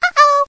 Звуки уведомлений Андроид